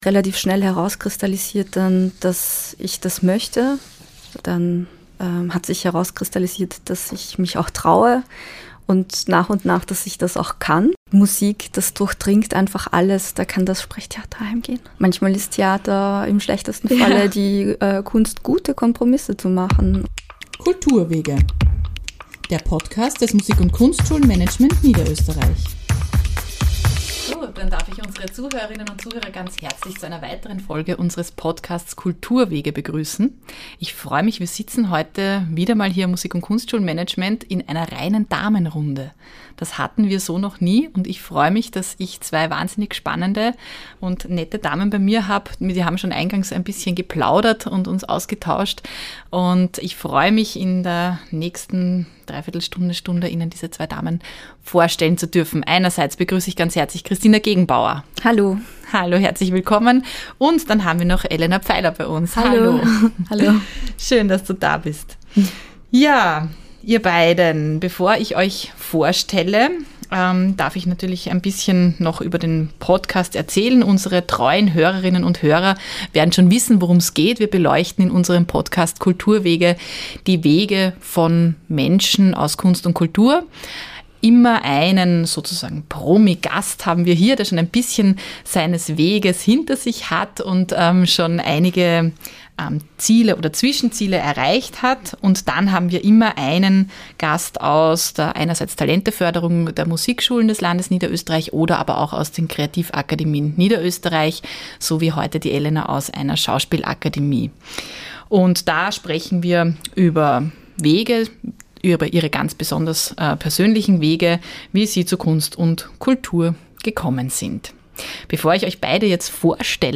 In dieser Folge gibt es geballte Frauenpower.